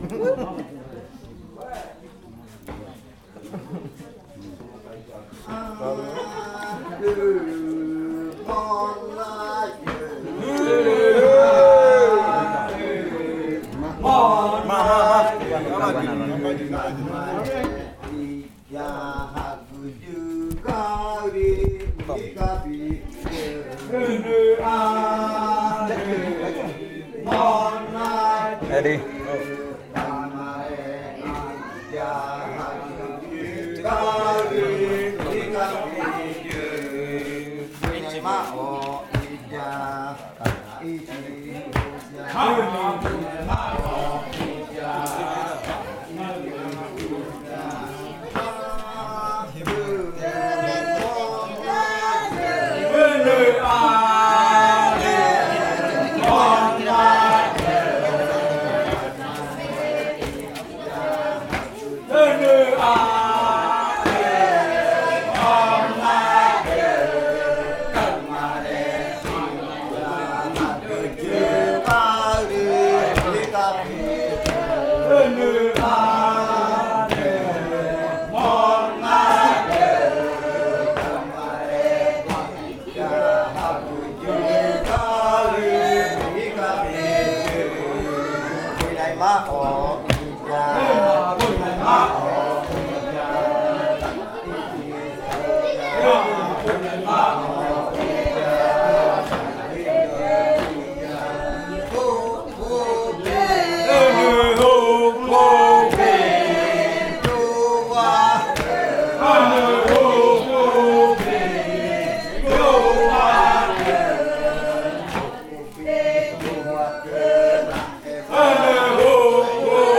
Canto de la variante muinakɨ
Leticia, Amazonas